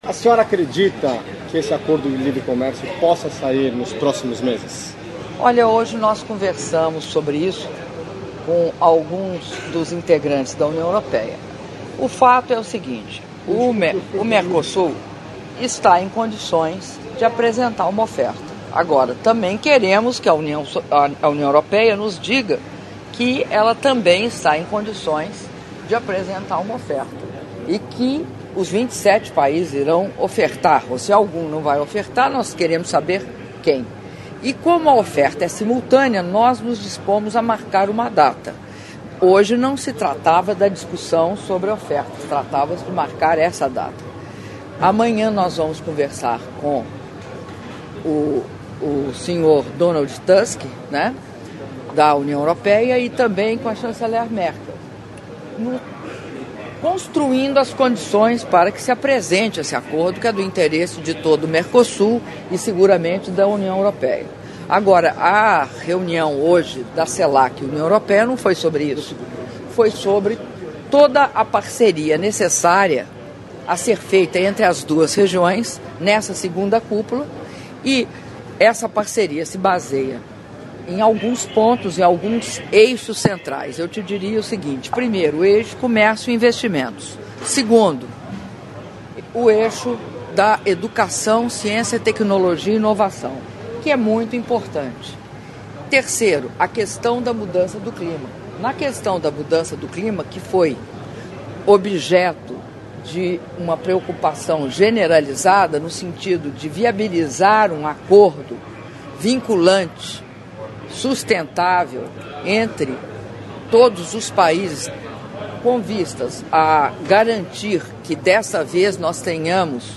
Áudio da entrevista coletiva concedida pela Presidenta da República, Dilma Rousseff, após reuniões da Cúpula UE-CELAC - Bruxelas/Bélgica (04min03s)